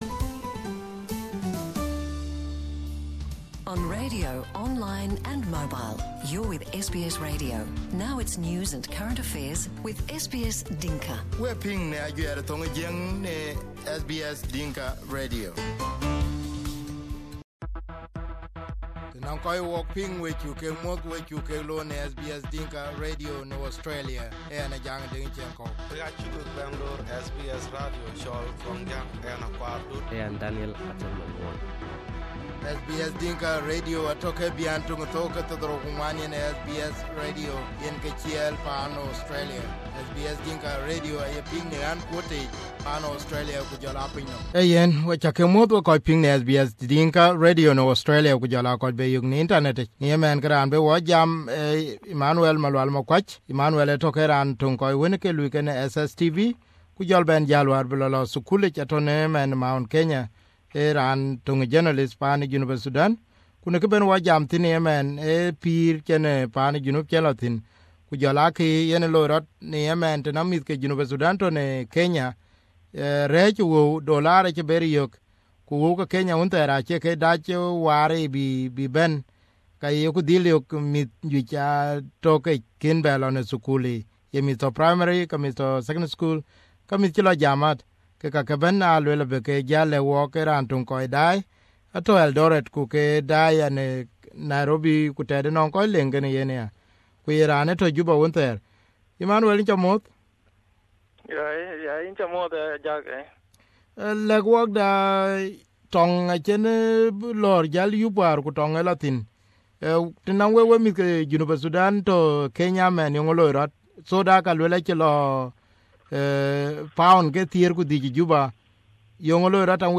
Between 2014 and 2015, more than 100 South Sudanese boys were forced to undergo illegal circumcision in the Kenyan town of Eldoret. In an interview with one of the witnesses who attended the case, we hear about how these kids were lured into something they didn't know about, nor were the parents aware of what happened.